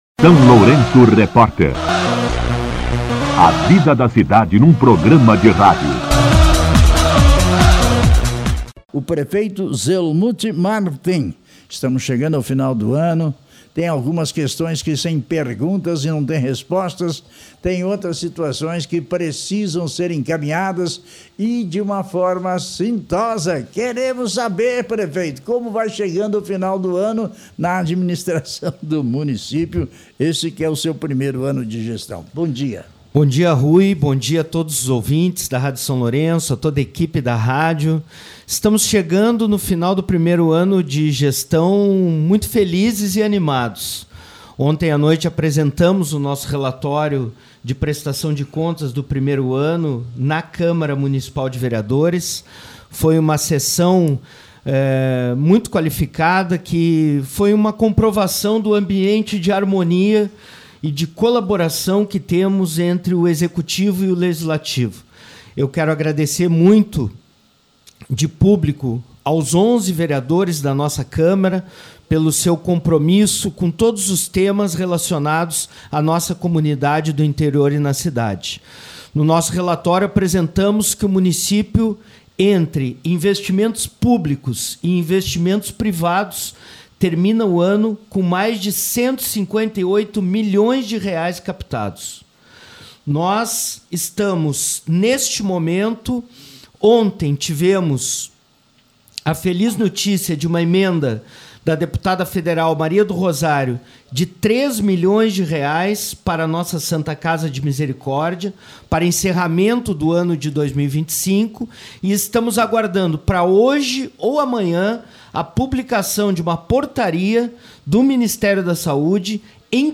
Entrevista com o Prefeito Zelmute Marten
Entrevista-prefeito-16.mp3